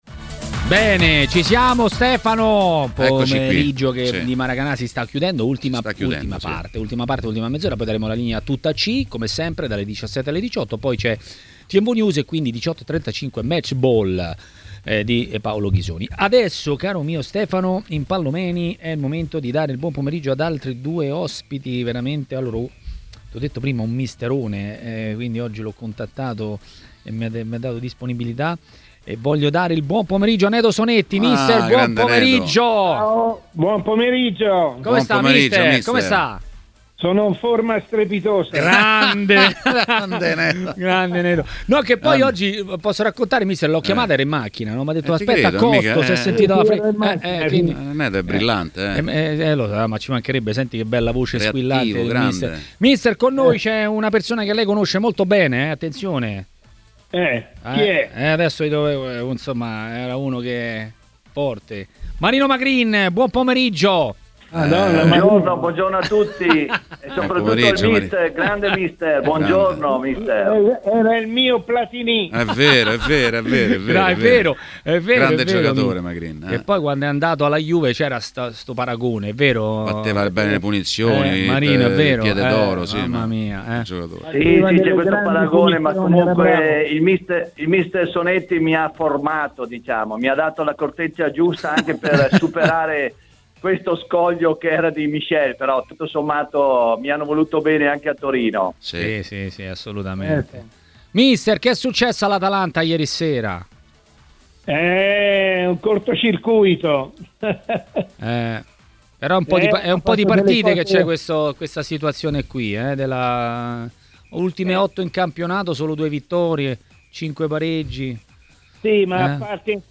Mister Nedo Sonetti ha detto la sua sui temi del giorno a TMW Radio, durante Maracanà.